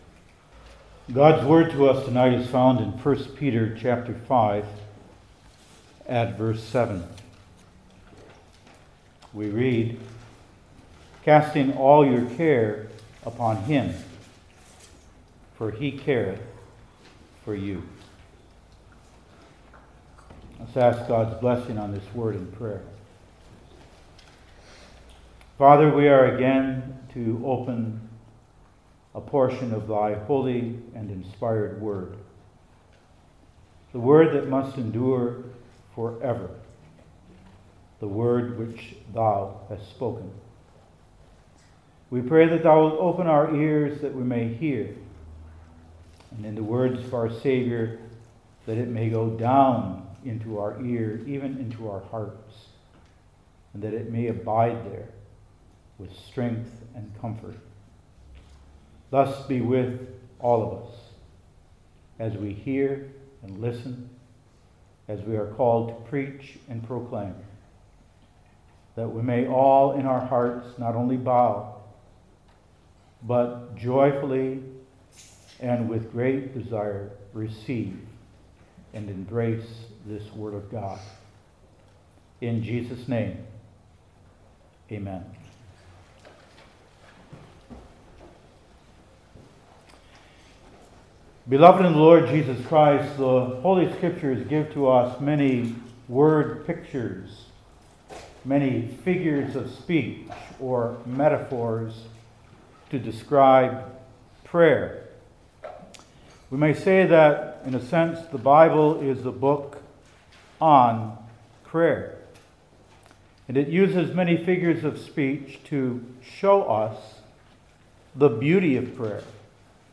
Passage: I Peter 5:7 Service Type: New Testament Individual Sermons I. What?